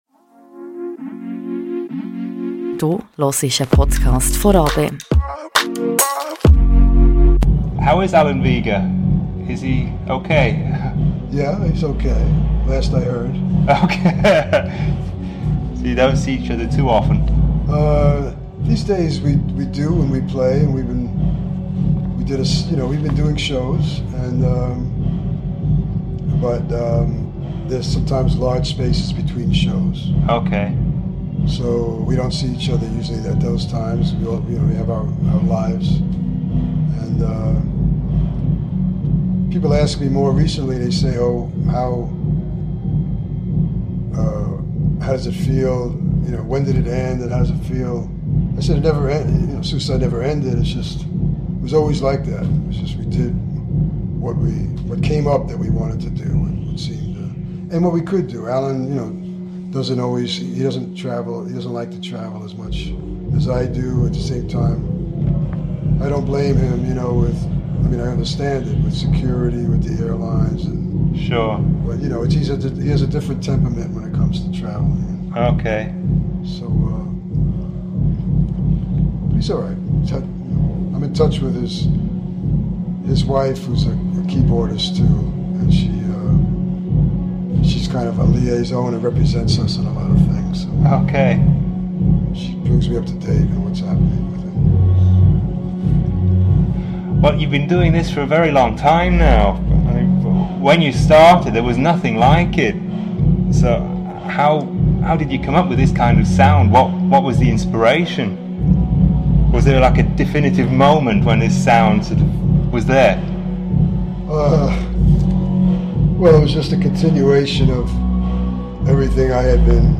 Beschreibung vor 1 Jahr A while ago Martin Rev – one half of Suicide – came to Bern to play at a festival in the Dampfzentrale. After the concert I got to sit down with him for an interview, that turned into a conversation about more than just music. The sound quality is not stellar, as other bands were still playing, but in my opinion that befits the charm of the moment.